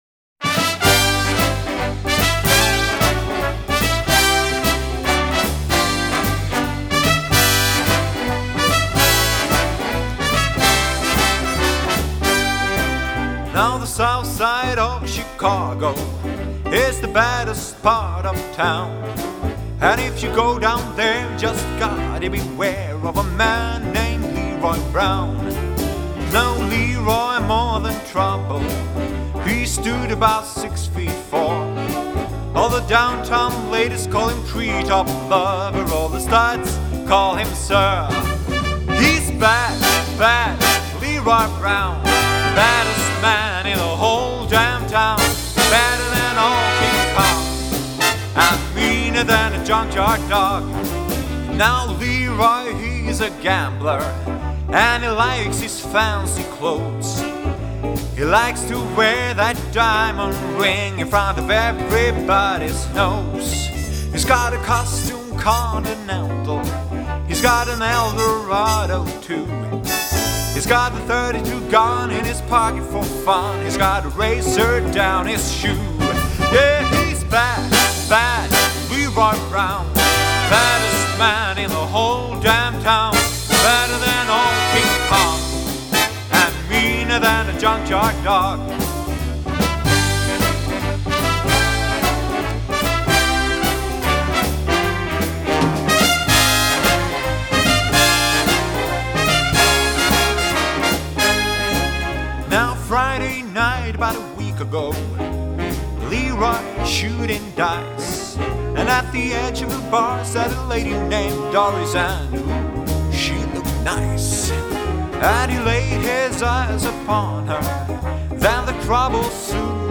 • Bigband